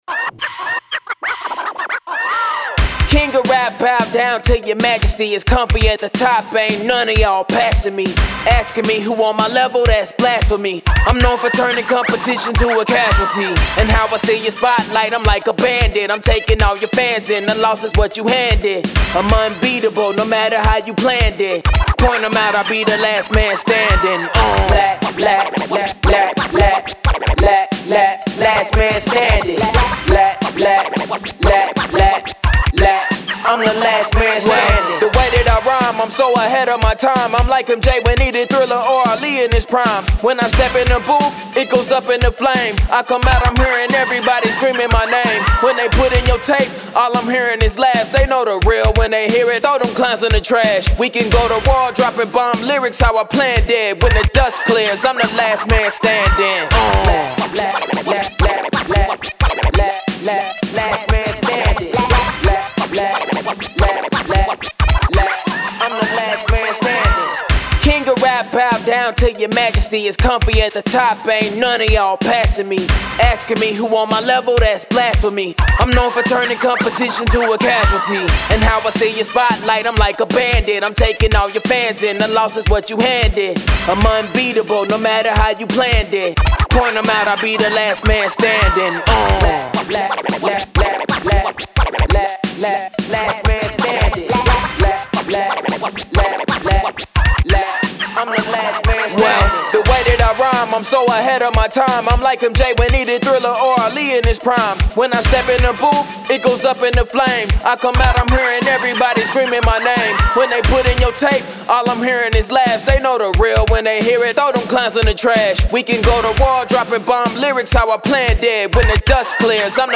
Hip Hop, 80s
A minor